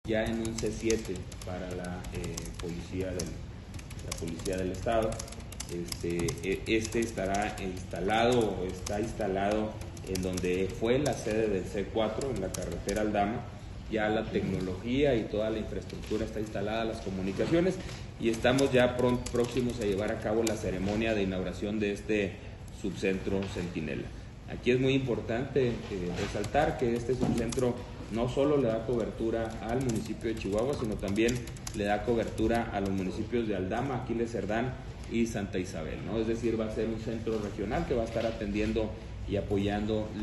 AUDIO: GILBERTO LOYA, SECRETARÍA DE SEGURIDAD PÚBLICA DEL ESTADO (SSPE)